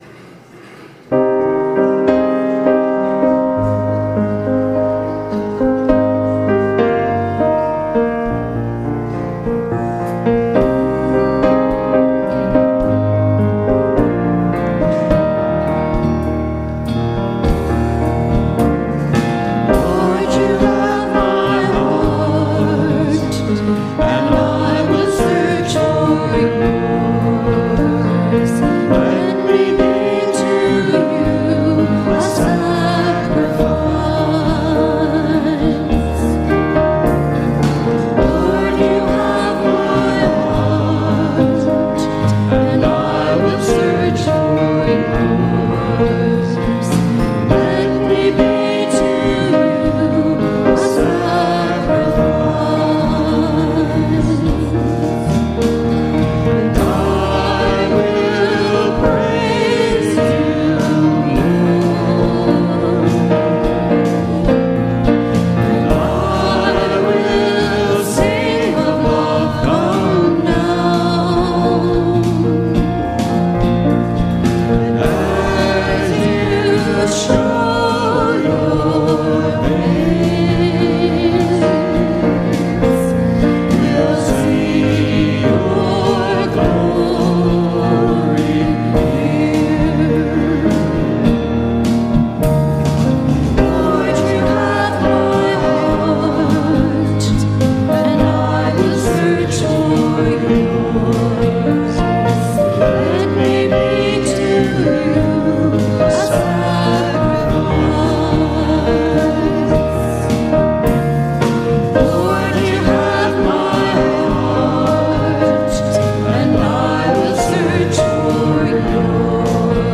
Podcast from Christ Church Cathedral Fredericton
WORSHIP - 10:30 a.m. Second after Epiphany